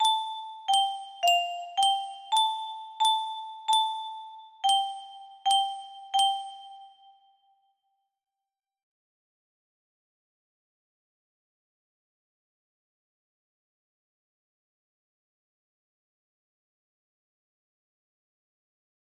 none music box melody